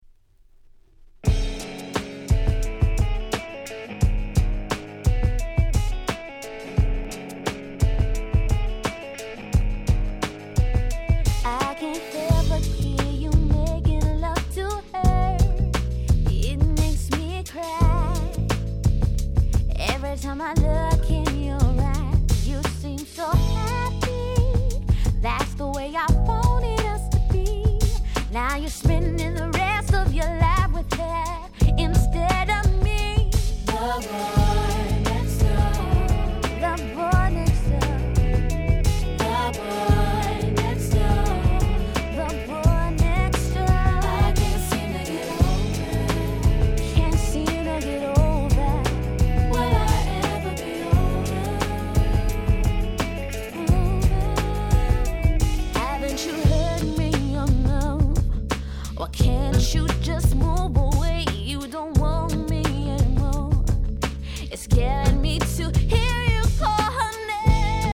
98' Nice R&B LP !!
統一したNeo Soul感が素晴らしい名盤中の名盤です！